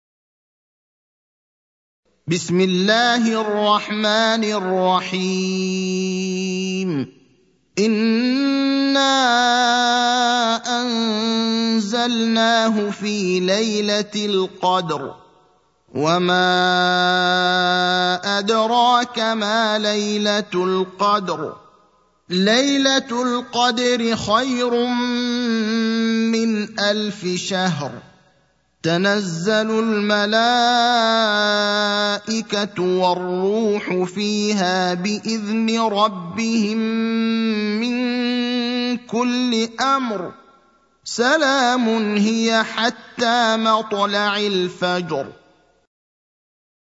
المكان: المسجد النبوي الشيخ: فضيلة الشيخ إبراهيم الأخضر فضيلة الشيخ إبراهيم الأخضر القدر (97)